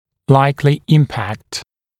[‘laɪklɪ ‘ɪmpækt][‘лайкли ‘импэкт]вероятный эффект, вероятное воздействие